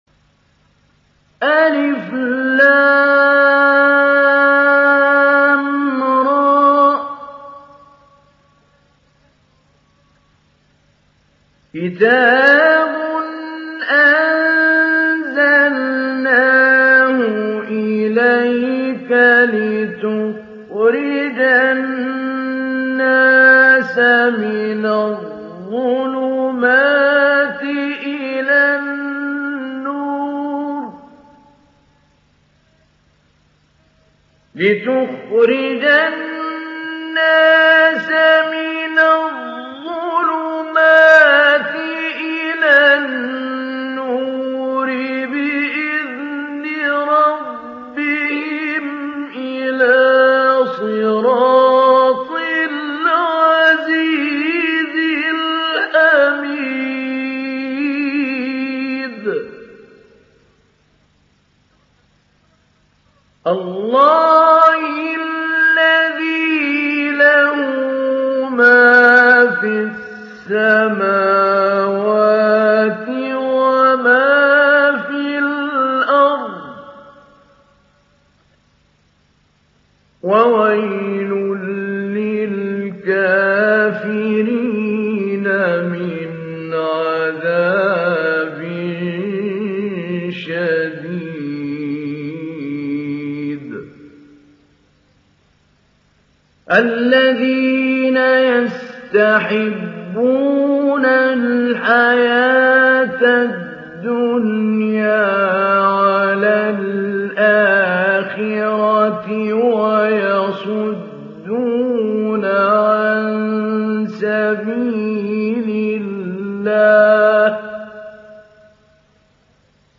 ডাউনলোড সূরা ইব্রাহীম Mahmoud Ali Albanna Mujawwad